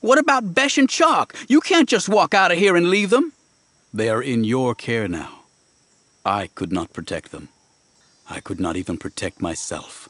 Shatterpoint abridged audiobook